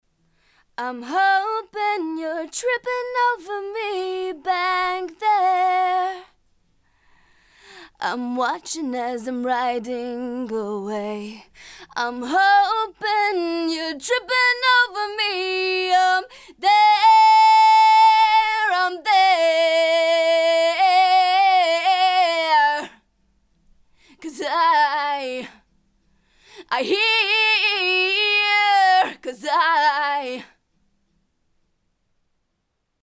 B172A 17 Female Vocal
b172a-17-f-voc.mp3